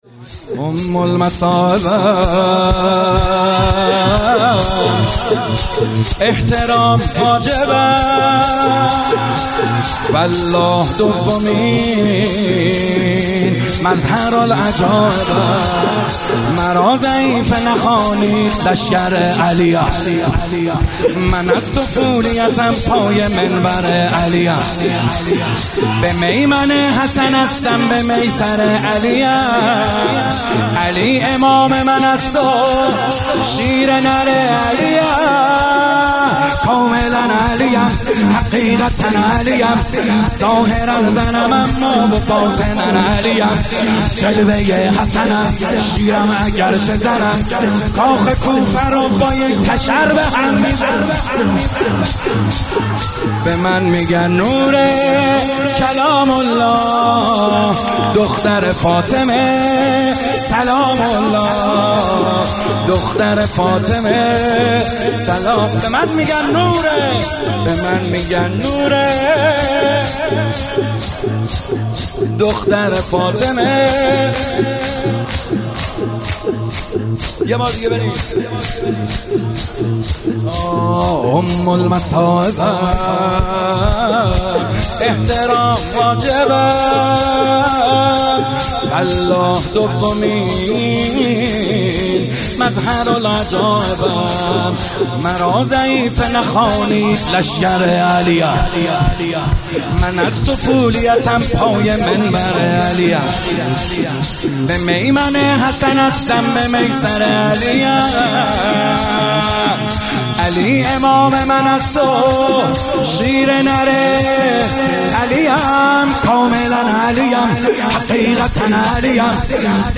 همچنین این مداح اهل بیت (ع) به دلیل جسارت اخیر صورت گرفته به ساحت حضرت زینب (س) نوحه‌ای نیز در رثای این بانوی باکرامت اسلام خواند که شعر آن به شرح زیر است: